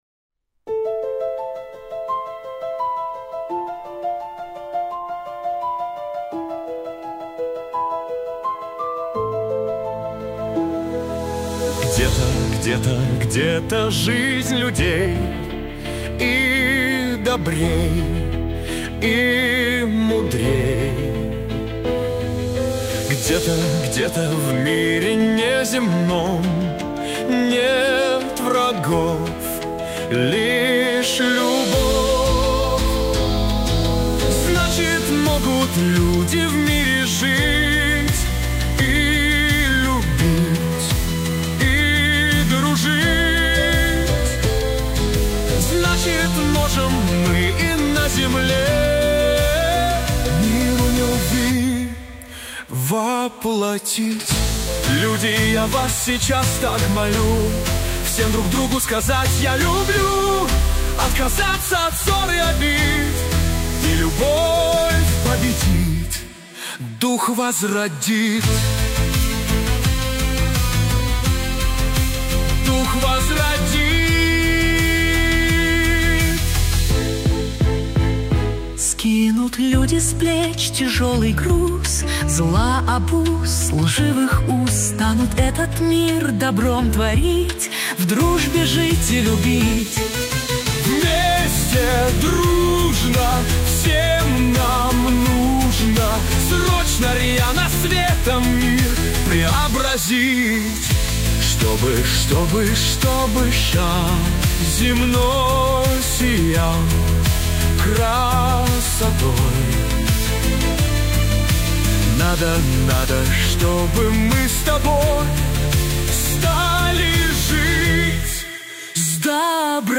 кавер-версия на мотив песни